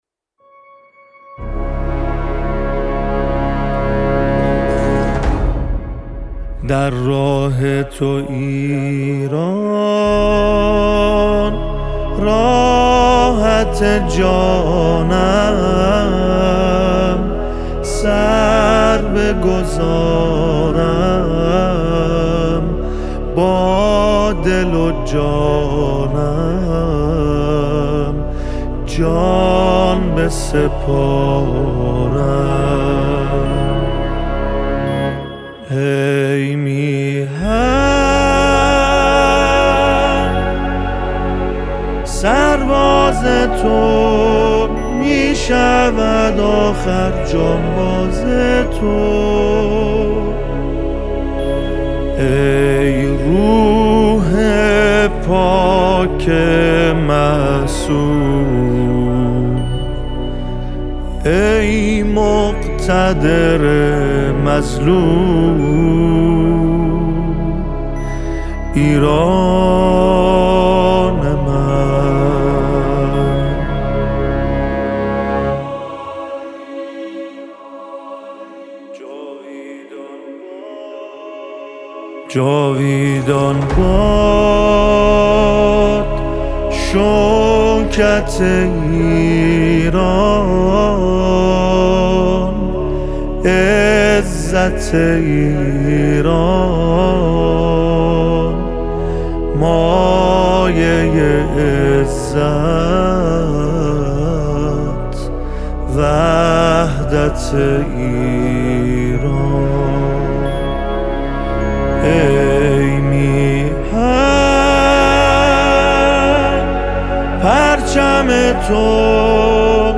نماهنگ حماسی